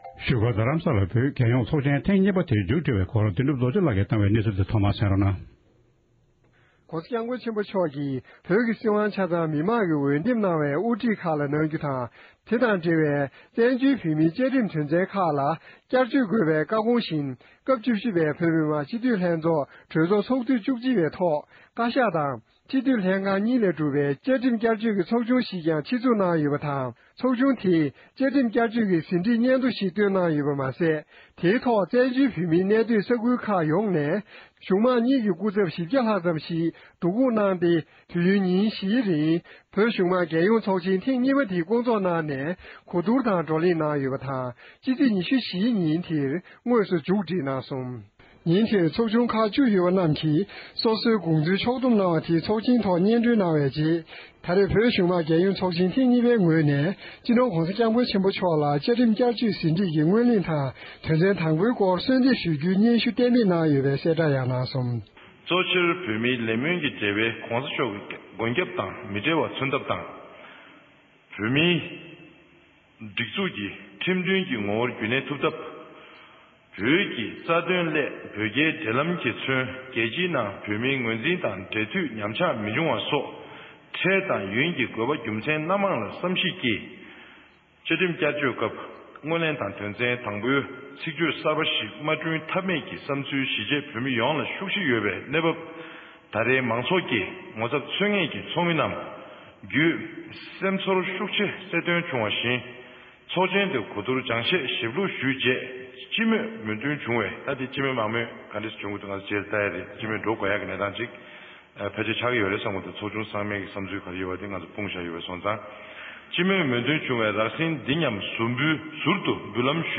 ཚོགས་ཞུགས་བ་ཁག་ཅིག་གི་ལྷན་གླེང་མོལ་ཞུས་པར་གསན་རོགས༎